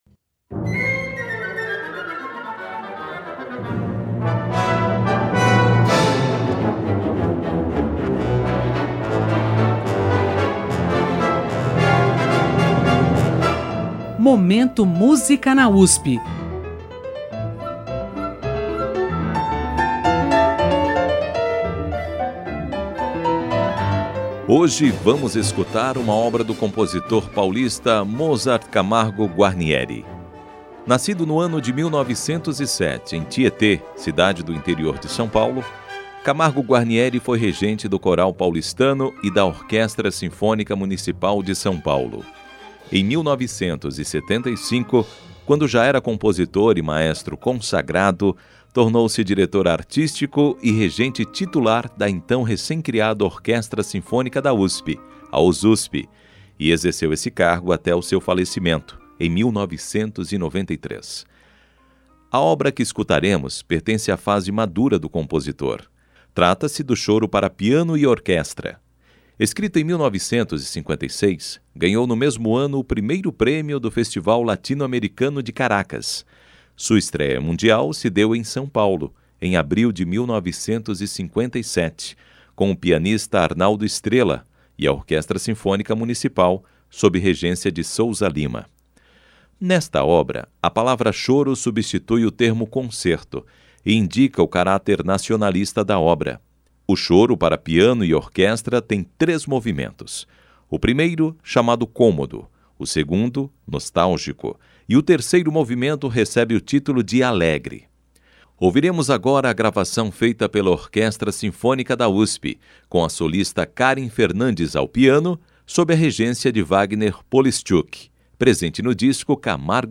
para Piano e Orquestra
interpretado pela Orquestra Sinfônica da USP (Osusp)
O concerto da Osusp exibido no programa